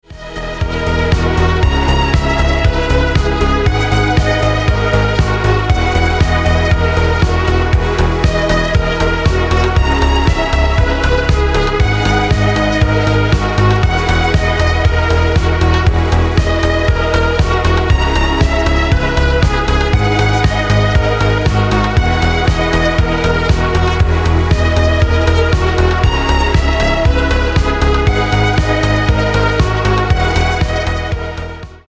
• Качество: 320, Stereo
Electronic
без слов